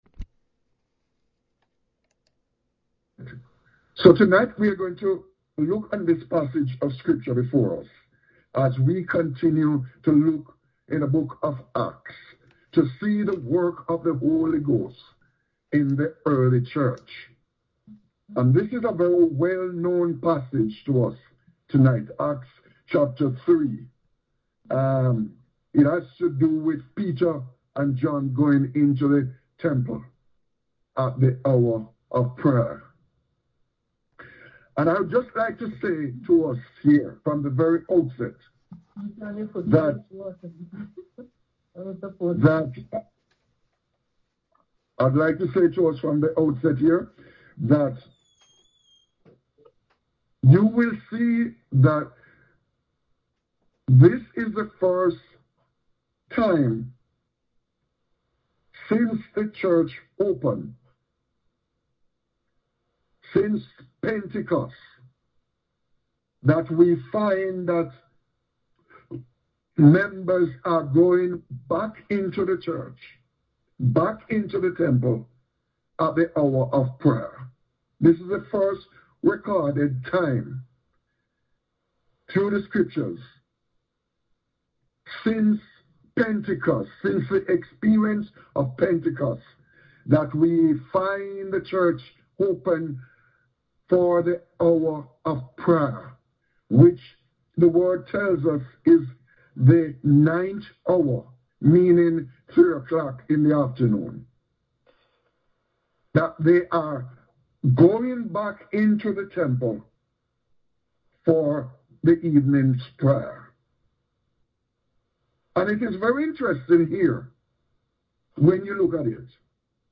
Acts Bible Study